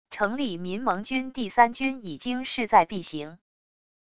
成立民盟军第三军已经势在必行wav音频生成系统WAV Audio Player